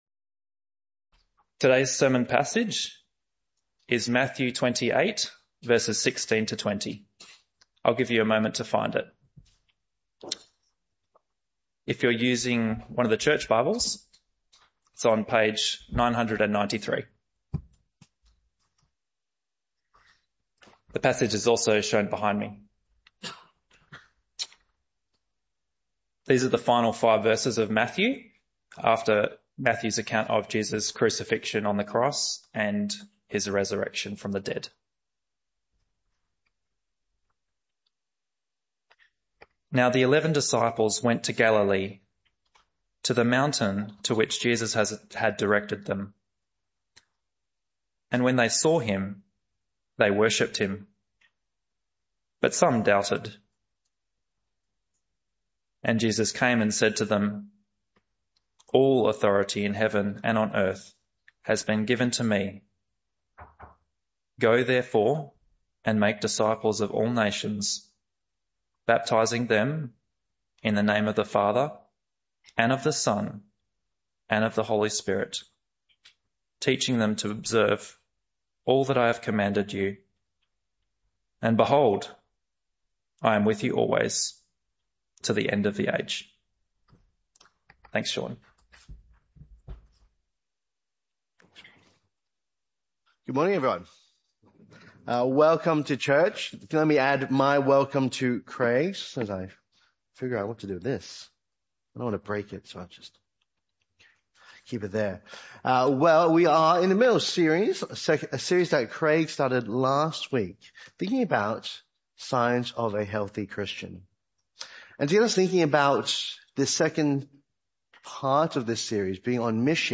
This talk was part of the AM & PM Sermon series entitled 5 Signs Of A Healthy Christian.